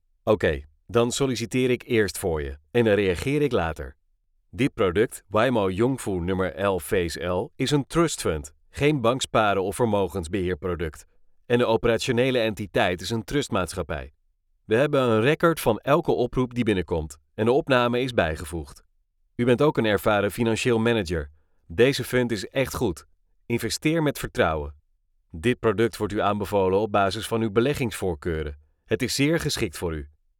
数据堂TTS录音棚通过清华大学建筑环境检测中心检测，达到专业级NR15声学标准，混响时间小于0.1秒，背景噪音小于20dB(A)。
荷兰语，低沉男声